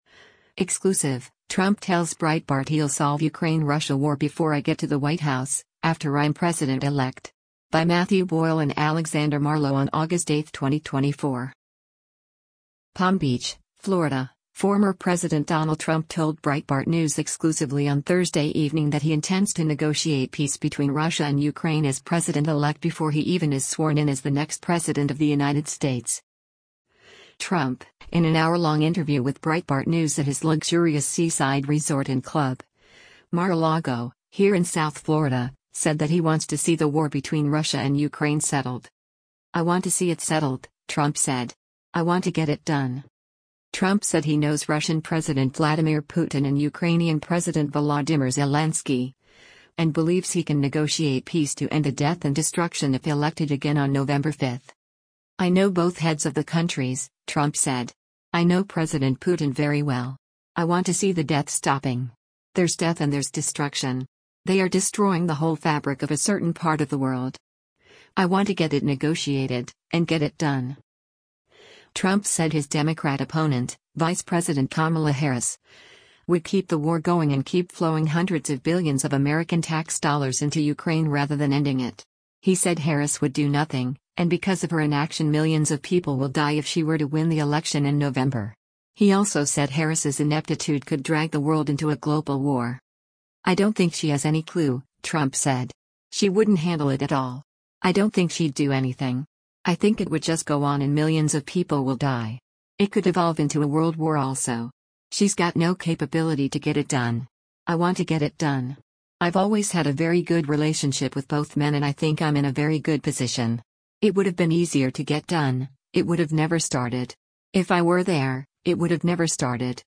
Trump, in an hourlong interview with Breitbart News at his luxurious seaside resort and club, Mar-a-Lago, here in South Florida, said that he wants to see the war between Russia and Ukraine “settled.”